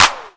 Claps
JJClap (15).WAV